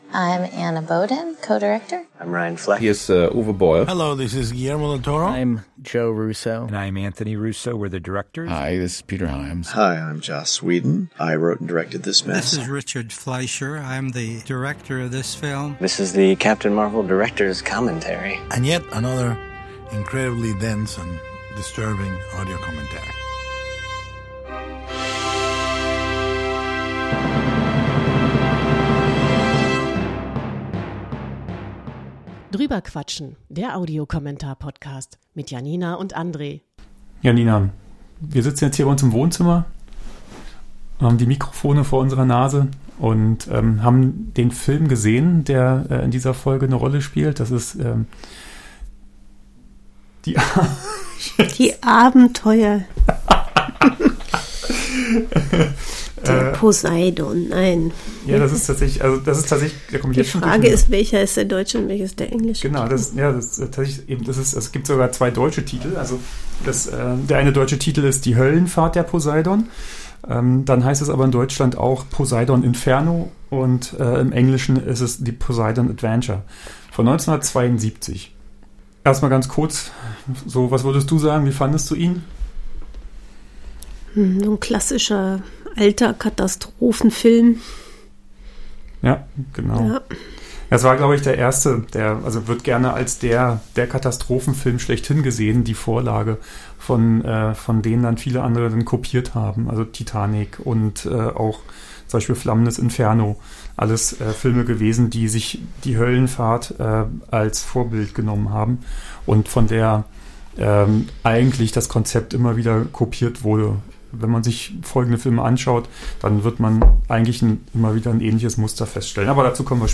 Wir testen noch mit unserem technischen Setup und sammeln Erfahrungen.